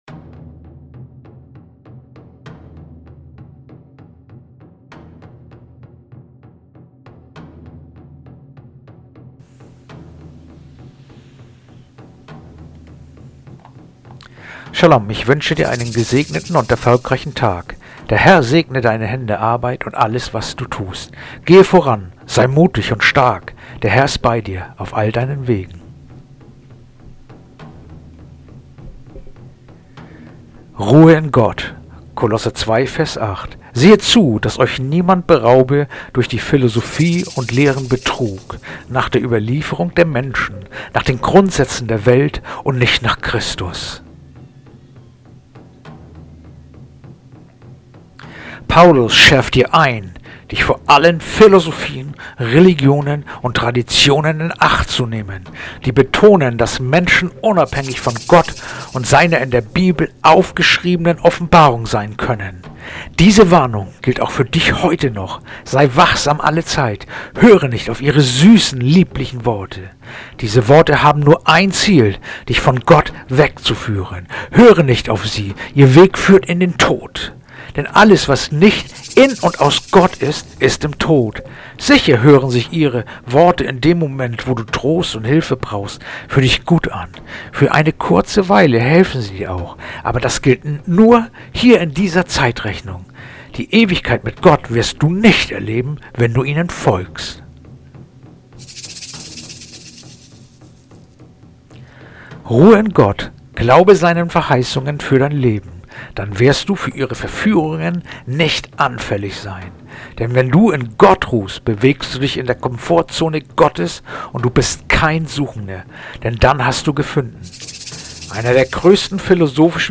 heutige akustische Andacht
Andacht-vom-15.-November-Kolosser-2-8.mp3